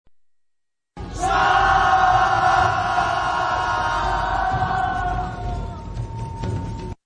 SFX战场进攻喊杀声音音效下载